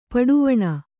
♪ paḍuvaṇa